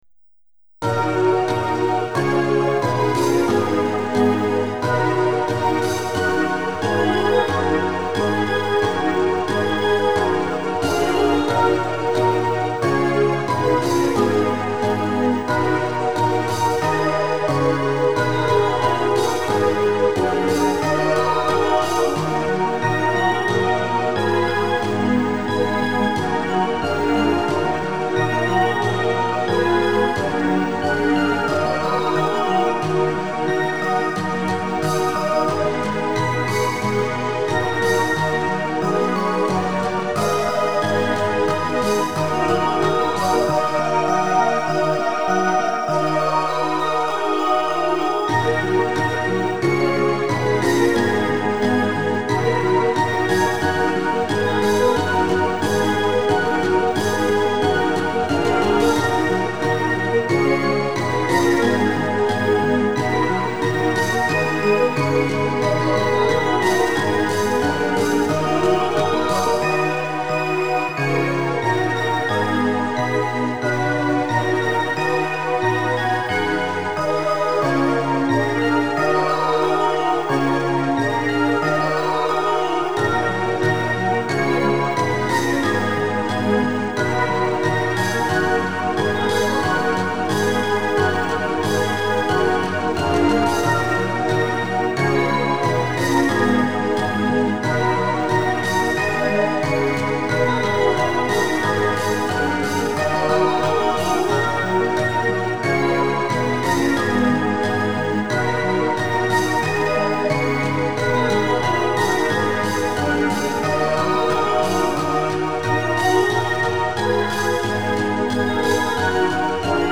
〜カラオケ版〜